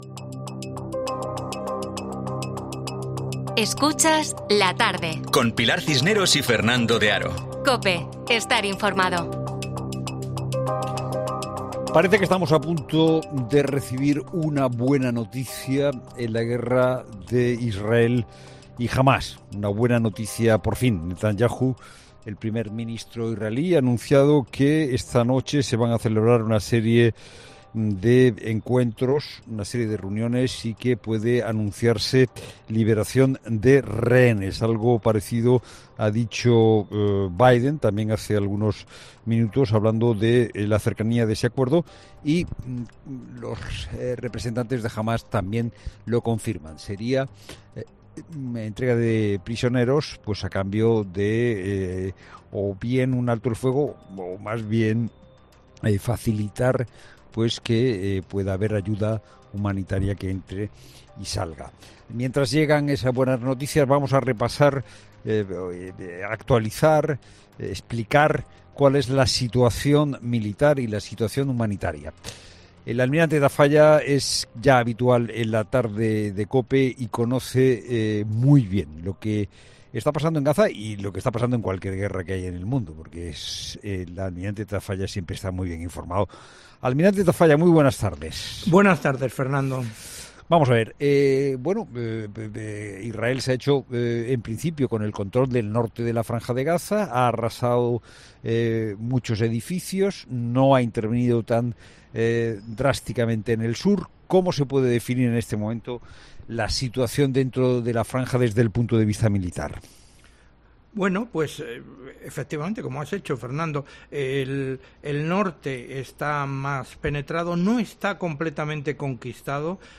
Hemos repasado cuál es la situación militar en esa zona junto al Almirante Ángel Tafalla , quien es ex segundo jefe del Estado Mayor de la Armada y del Mando Marítimo OTAN de Europa Sur. El Almirante nos ha contado que Israel posee el control del norte de la franja de Gaza por completo.